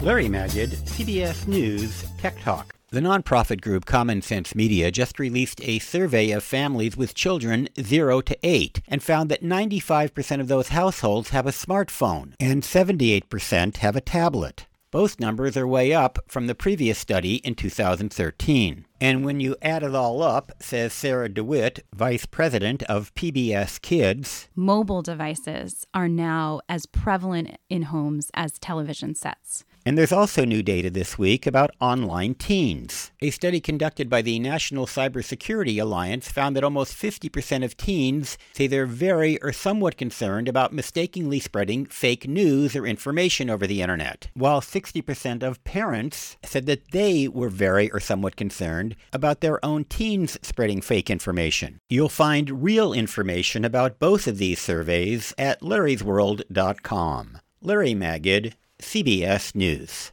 one-minute CBS News Radio segment about these new surveys